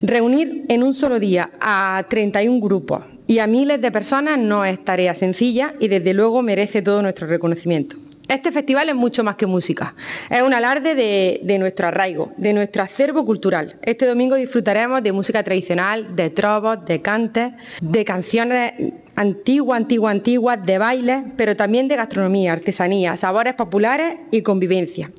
05-08_festival_alpujarra_diputada.mp3